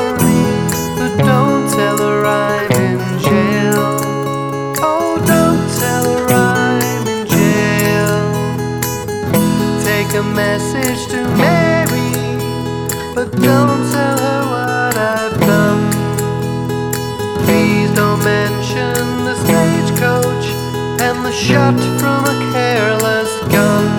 No Harmony Pop (1950s) 2:30 Buy £1.50